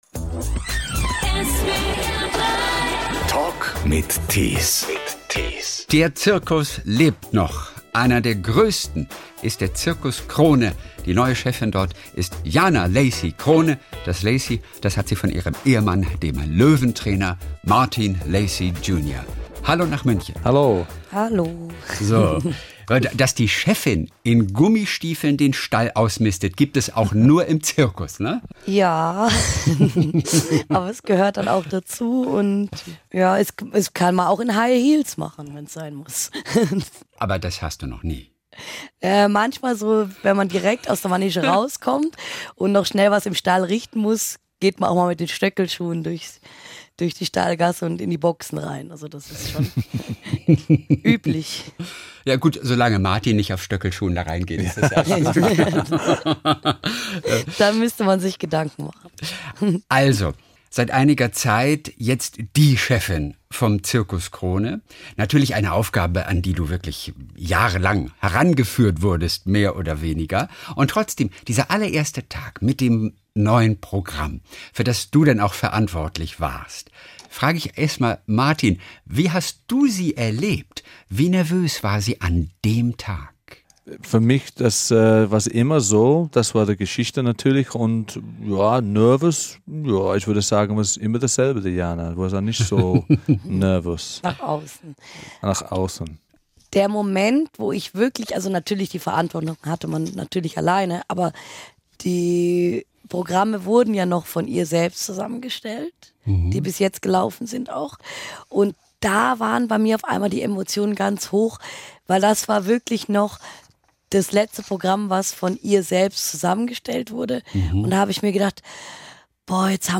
Der Talk mit interessanten Menschen